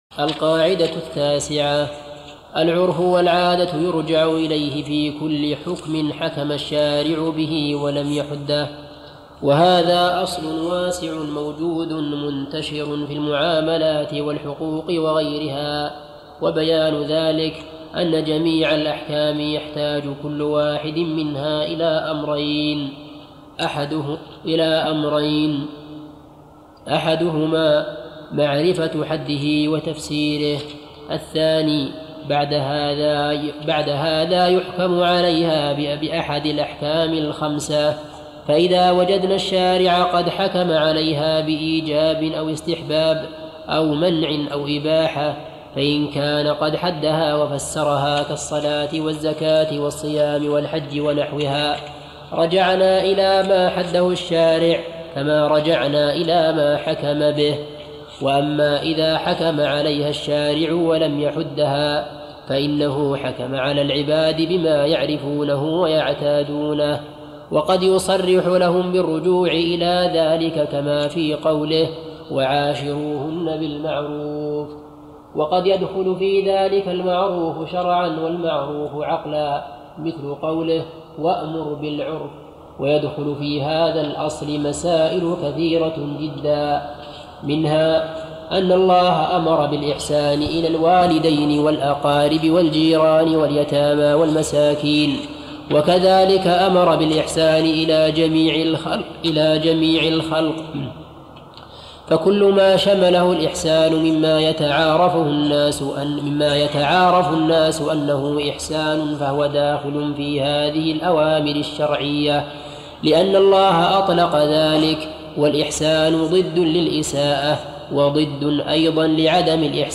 ارسل فتوى عبر "الواتساب" ينبوع الصوتيات الشيخ محمد بن صالح العثيمين فوائد من التعليق على القواعد والأصول الجامعة - شرح الشيخ محمد بن صالح العثيمين المادة 23 - 148 القاعدة التاسعة العرف والعادة يرجع إليه في كل حكم...